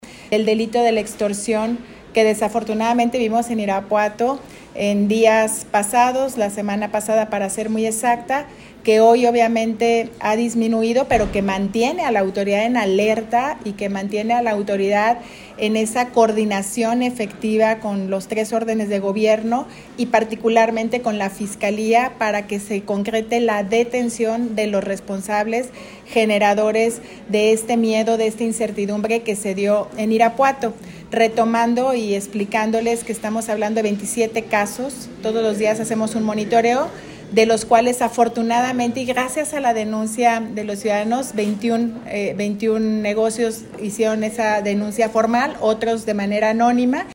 AudioBoletines
Lorena Alfaro García, presidenta municipal
Ricardo Benavides Hernández, secretario de seguridad ciudadana